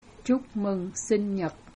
Here’s how it sounds with a Southern Viet accent:
Pronounce Chúc mừng sinh nhật